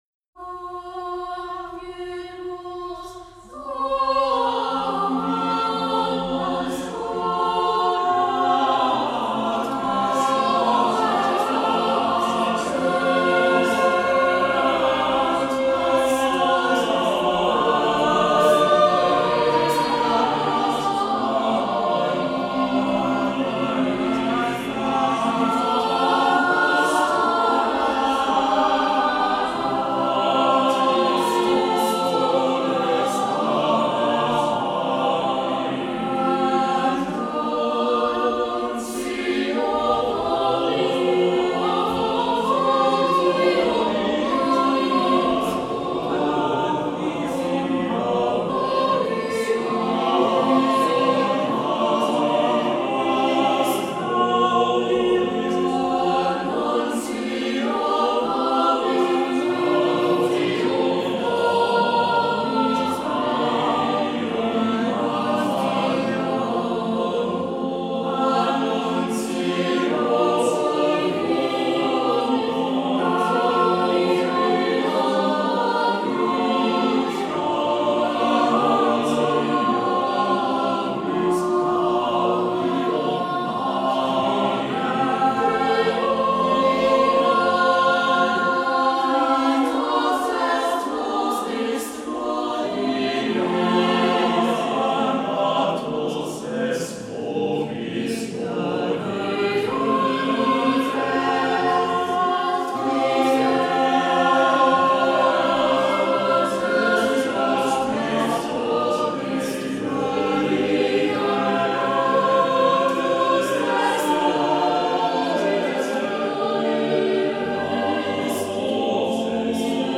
Die Wiener Sängerknaben - 11.12.
Die hier gesungene Motette von der Verkündigung der Hirten auf dem Feld hat zu Vaets Lebzeiten vermutlich ganz ähnlich geklungen.